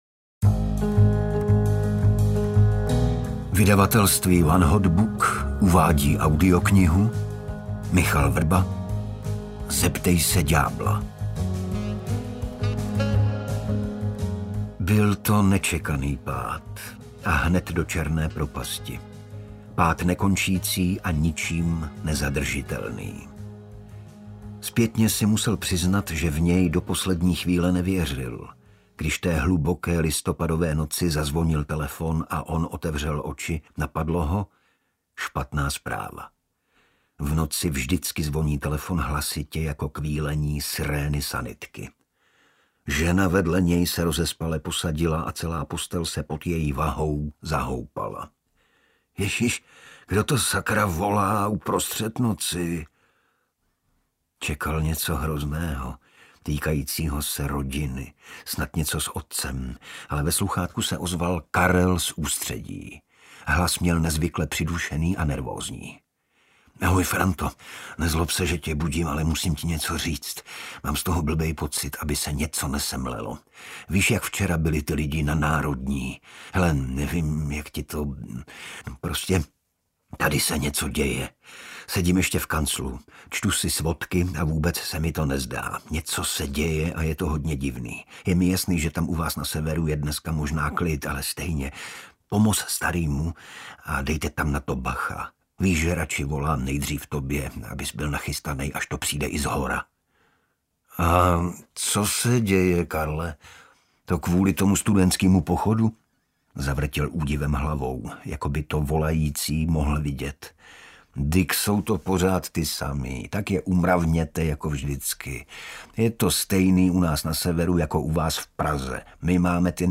Zeptej se ďábla audiokniha
Ukázka z knihy